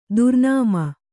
♪ durñama